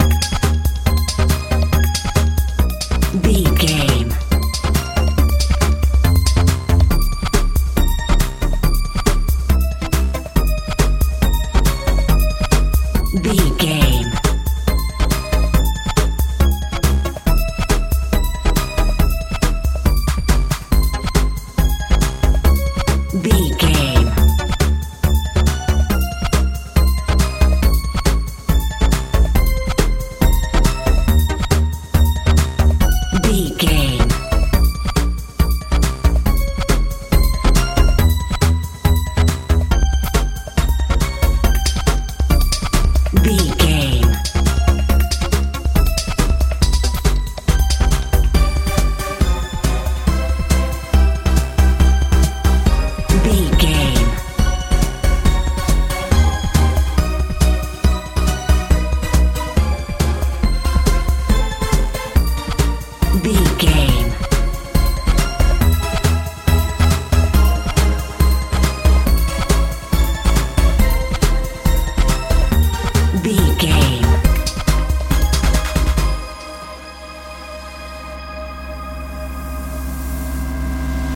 modern dance
Ionian/Major
A♭
magical
mystical
drums
synthesiser
bass guitar
80s
suspense
strange
tension
terror